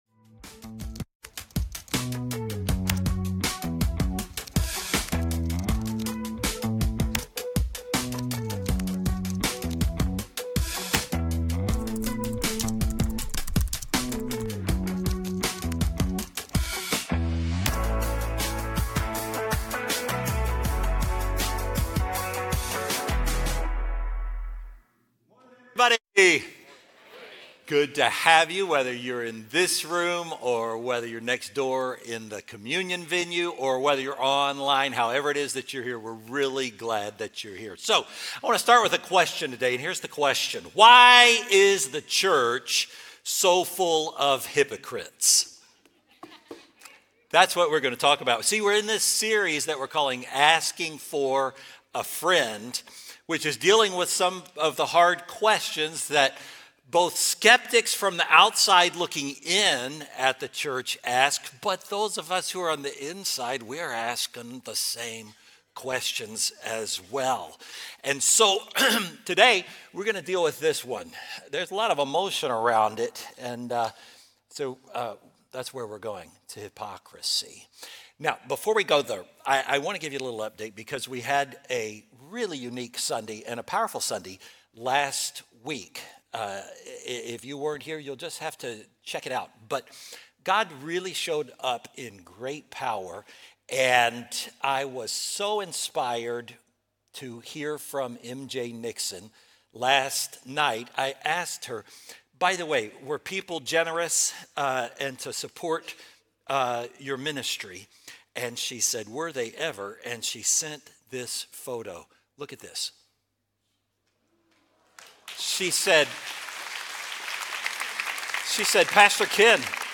Faithbridge Sermons Why Does the Church Have So Many Hypocrites?